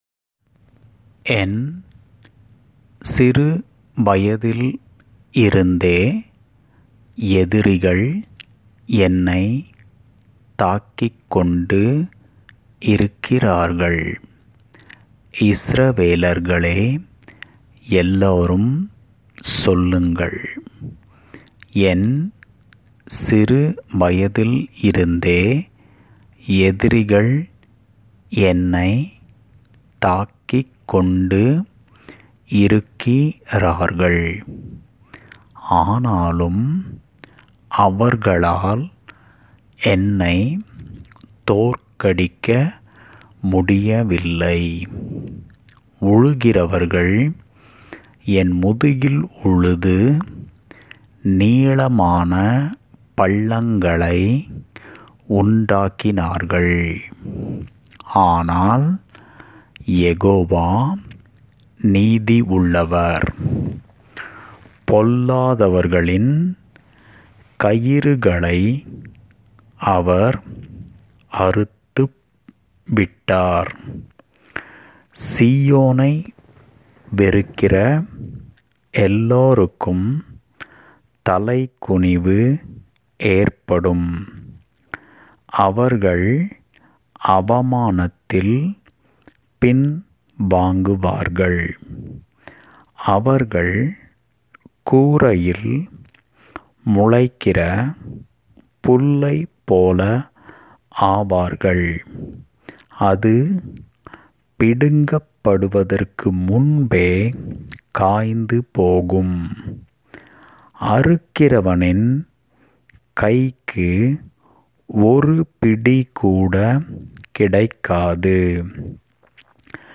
psalms129_tamilreading.mp3